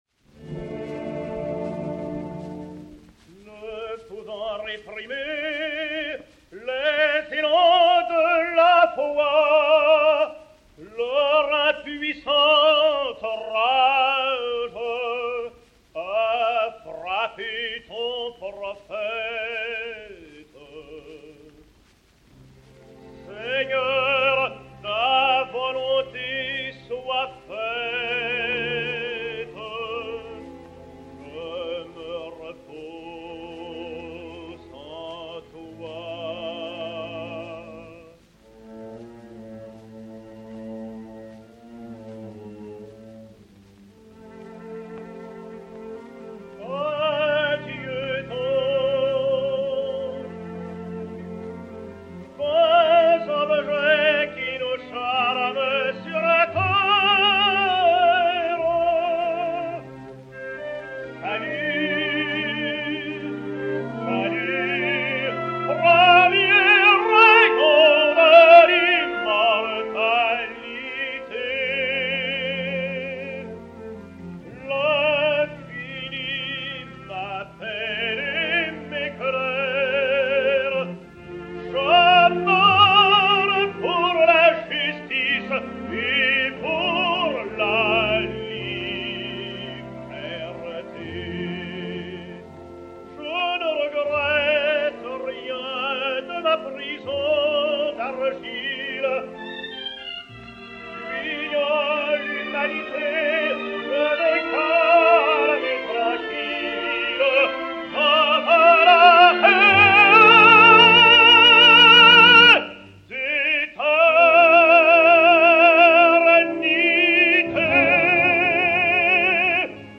Paul Franz (Radamès) et Orchestre